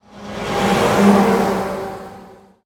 car10.ogg